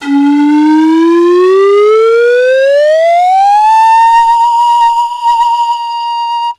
BAMBOOUP.wav